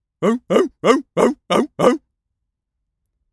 pgs/Assets/Audio/Animal_Impersonations/seal_walrus_bark_04.wav at master
seal_walrus_bark_04.wav